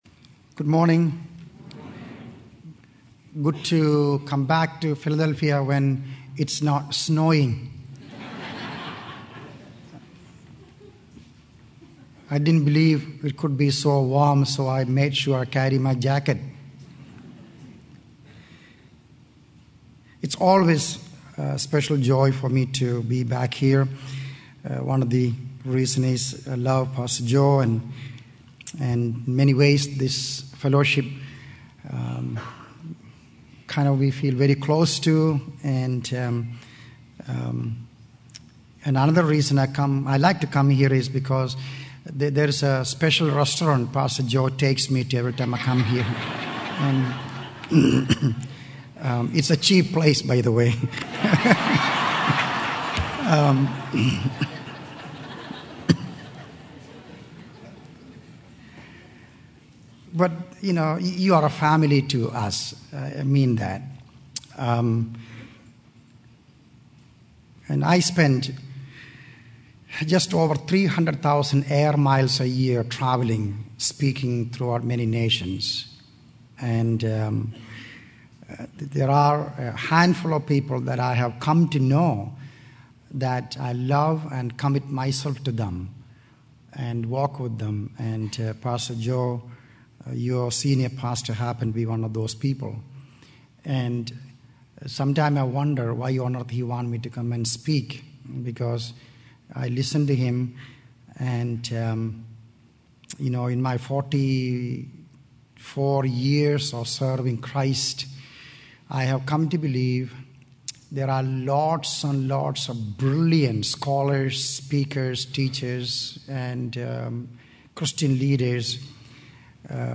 He urges the congregation to recognize that their lives are not their own and to embrace a lifestyle of prayer and obedience to God's will.